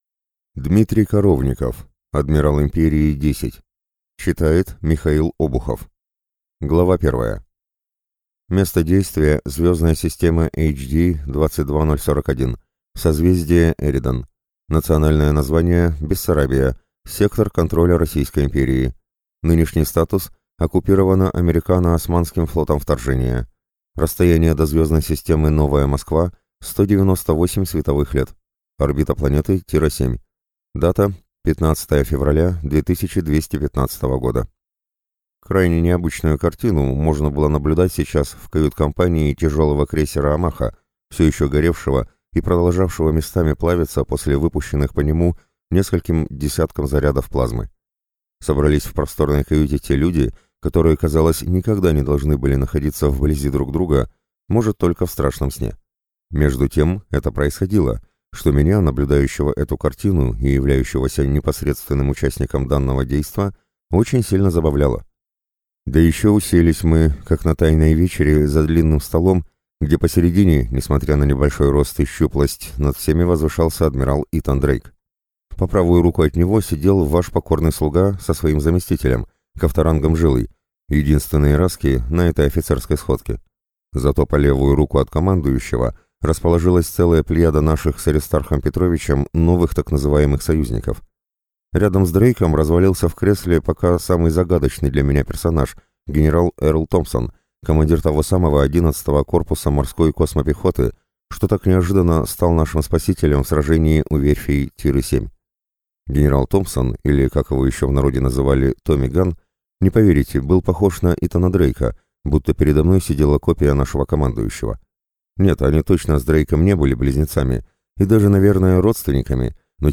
Аудиокнига Адмирал Империи – 10 | Библиотека аудиокниг
Прослушать и бесплатно скачать фрагмент аудиокниги